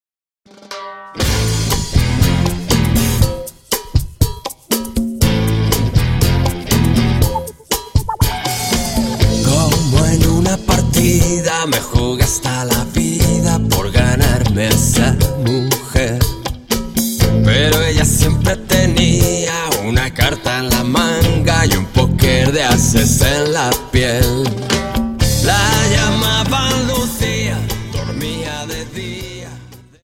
Dance: Cha Cha 31 Song